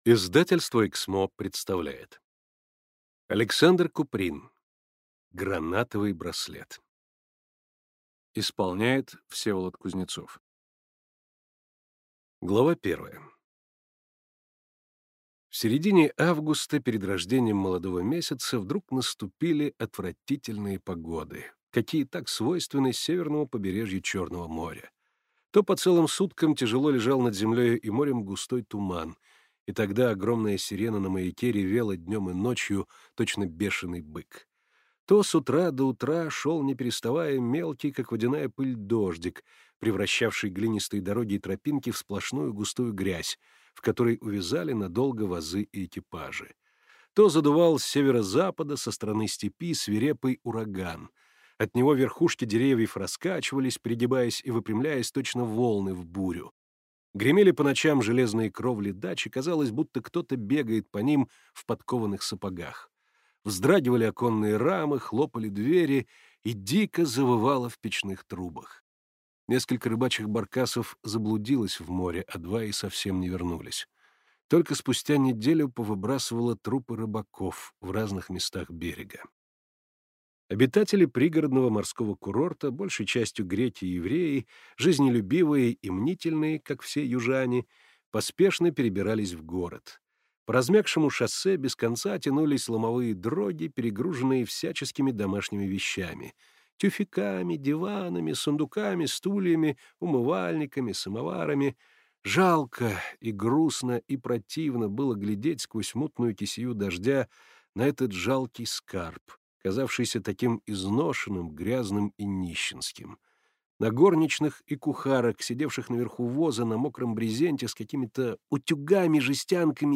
Аудиокнига Гранатовый браслет | Библиотека аудиокниг